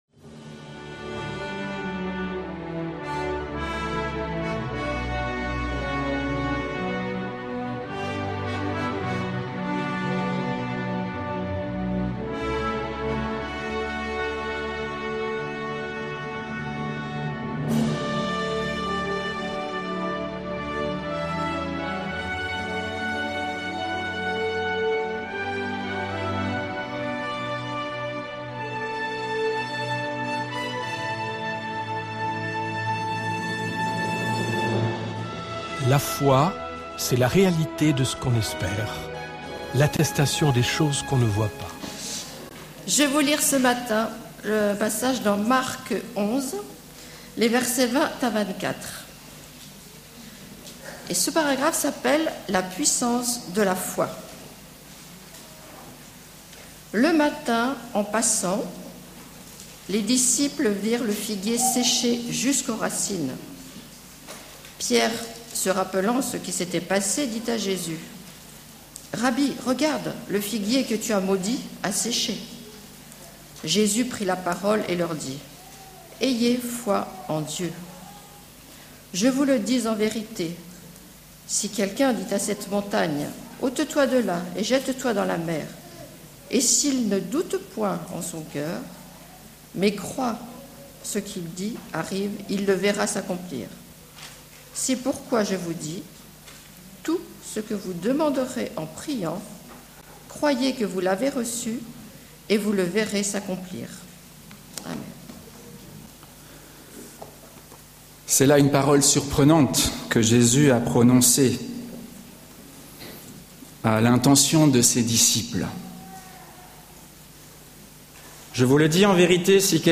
16-CULTE_Qu_il_te_soit_fait_selon_ta_foi.mp3